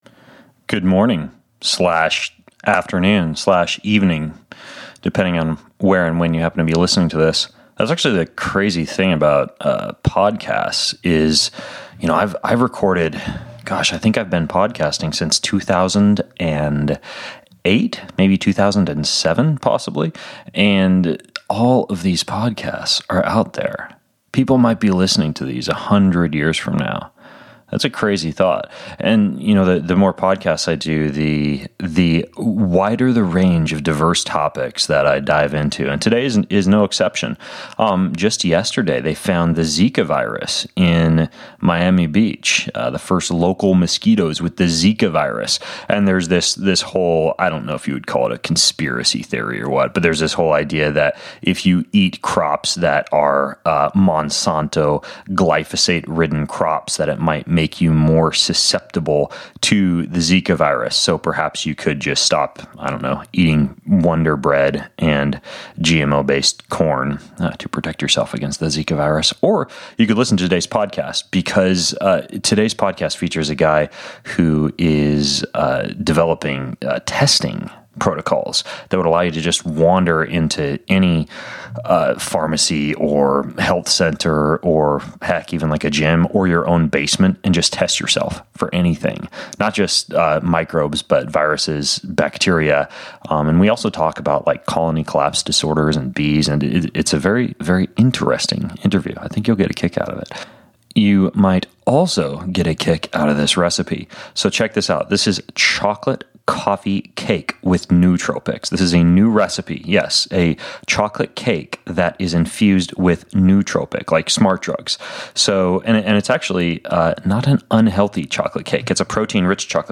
The Best Way To Test For Zika Virus & Beyond: One Single, Very Inexpensive Test That Can Measure EVERYTHING – An Interview